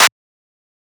TM88 - CLAP (2).wav